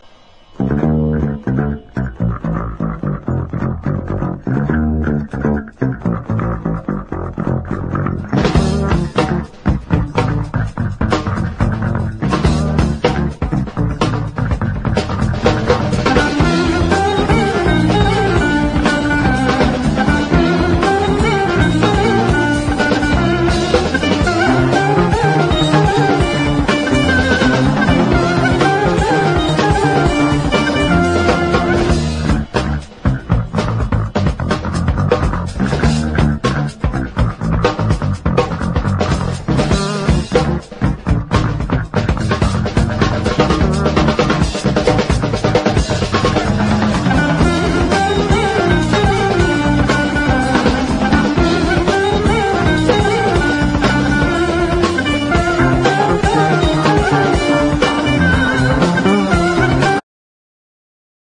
ROCK / 70'S / MODERN POP (US)
シンセサイザーを大胆に取り入れて初期のグラムなサウンドから一歩も二歩も踏み出した極上モダン・ポップ/ニュー・ウェイヴ！